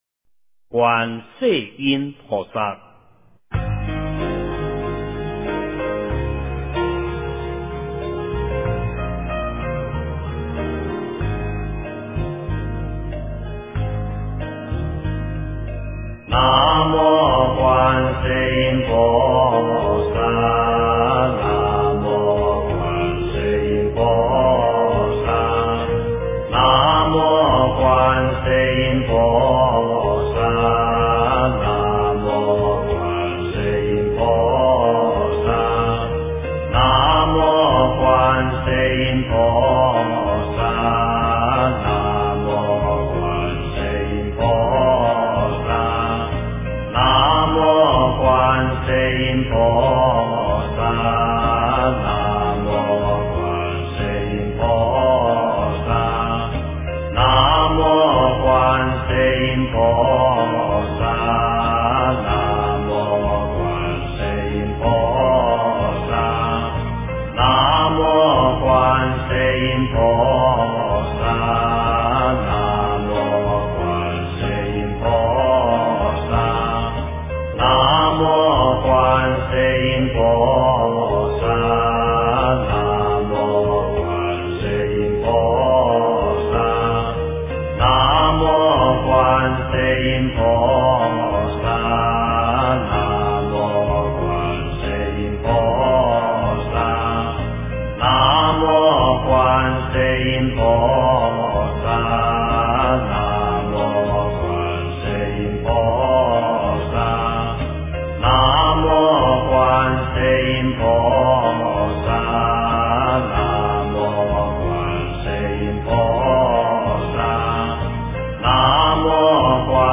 经忏